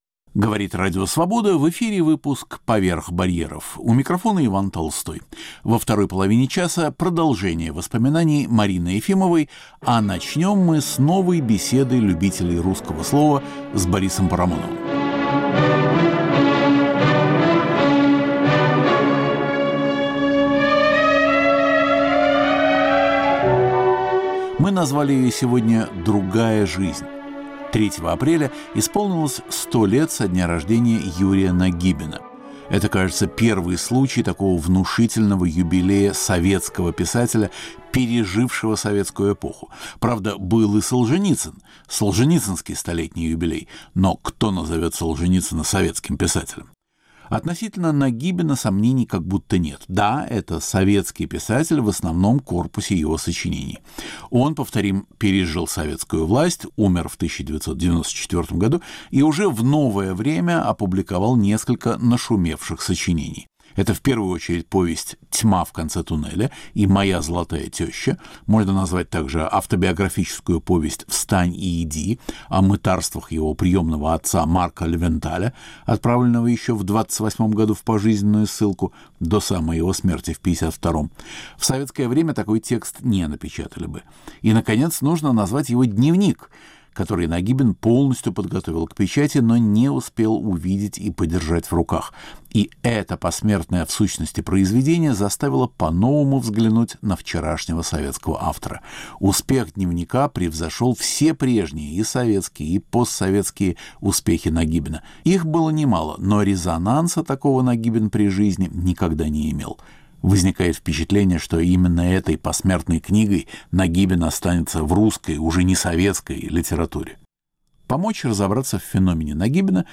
Беседа о писателе